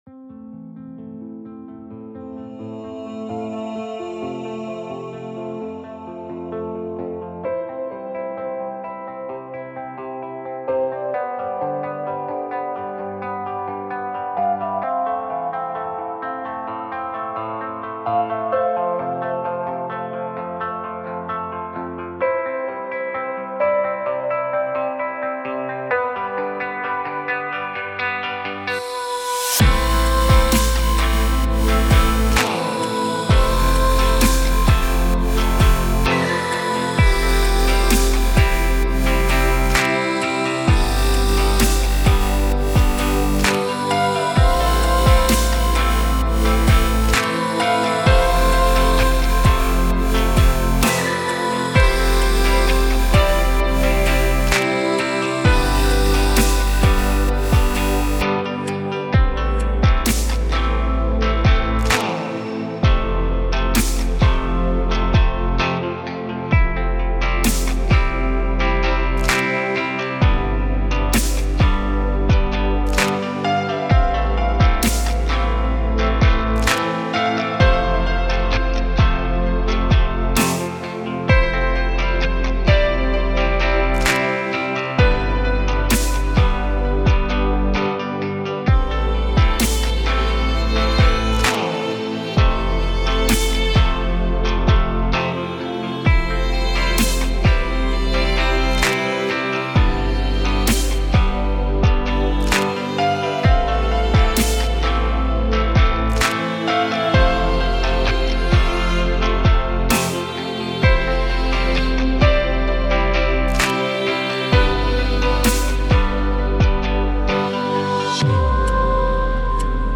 норвежской певицы